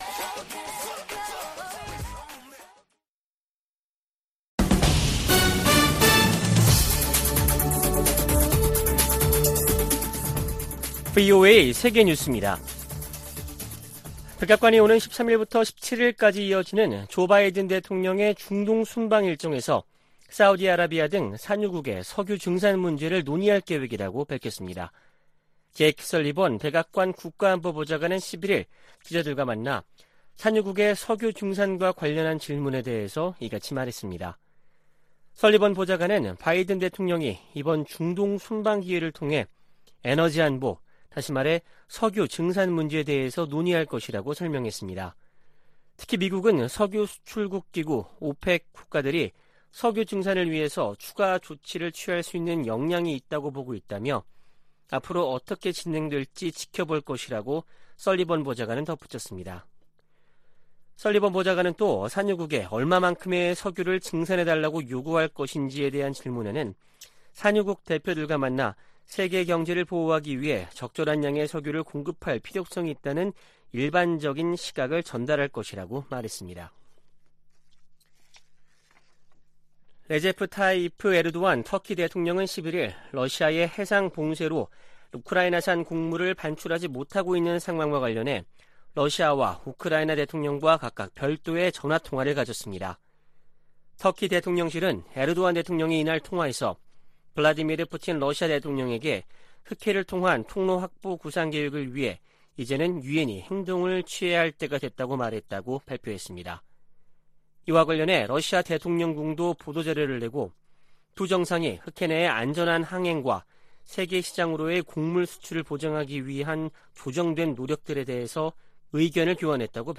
VOA 한국어 아침 뉴스 프로그램 '워싱턴 뉴스 광장' 2022년 7월 13일 방송입니다. 올 후반기 미-한 연합지휘소훈련이 다음달 22일부터 9월1일까지 실시될 전망입니다. 북한 군이 4주 만에 방사포 발사를 재개한 것은 한국을 실제로 타격하겠다는 의지와 능력을 과시한 것이라고 전문가들은 진단했습니다. 마크 에스퍼 전 미 국방장관은 주한미군에 5세대 F-35 스텔스기를 배치해야 한다고 주장했습니다.